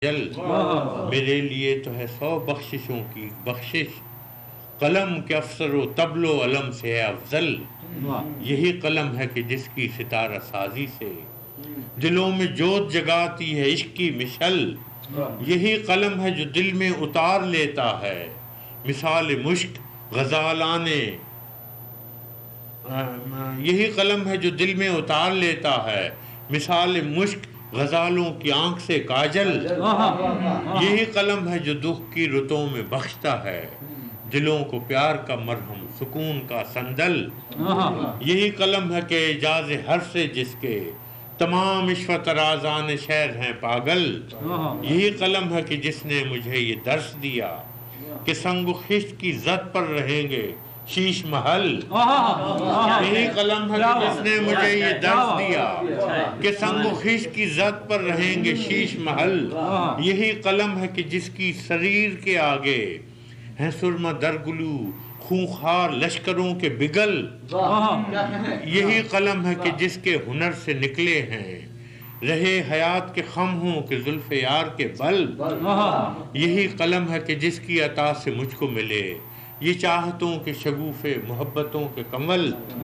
Recitation
It is posted on youtube as a recitation by faraz at a private gathering.